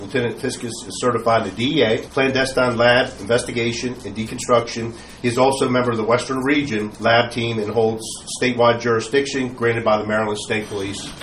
During Tuesday’s Cumberland City Council meeting, police chief Jim Pyles recognized the promotion of two officers to the office of Lieutenant.